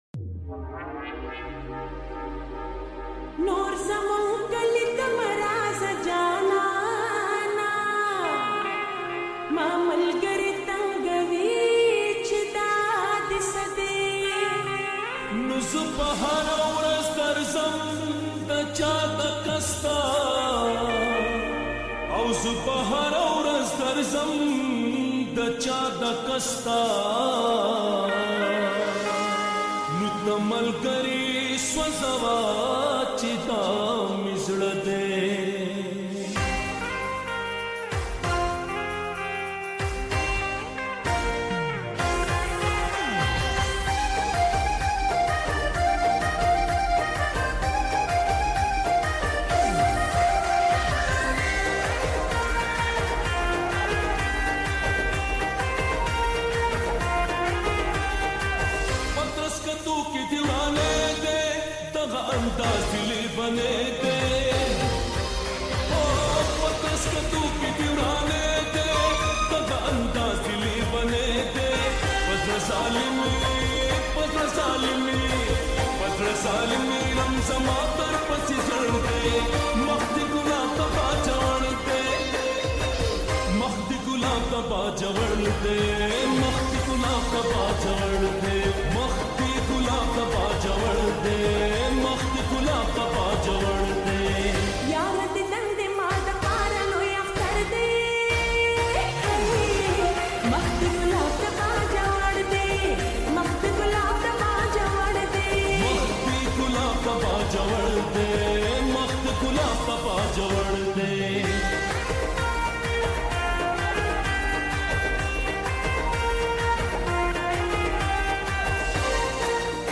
pashto full mast song